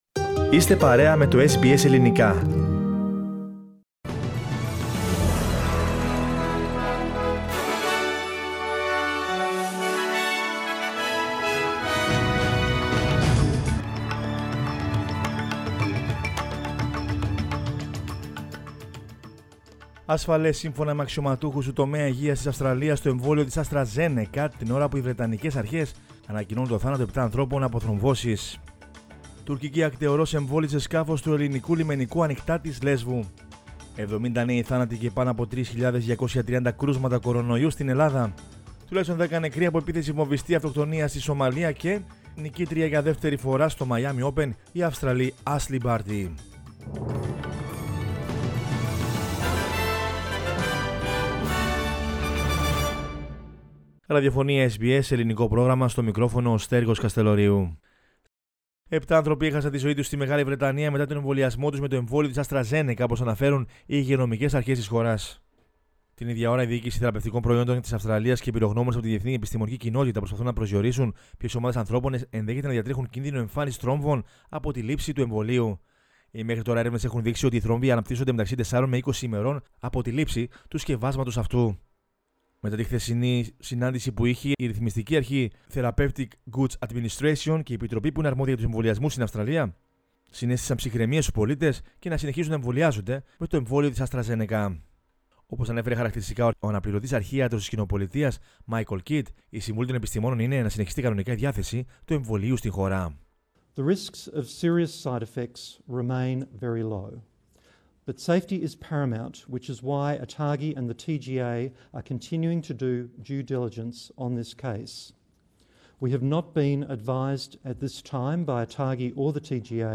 News in Greek from Australia, Greece, Cyprus and the world is the news bulletin of Sunday 4 April 2021.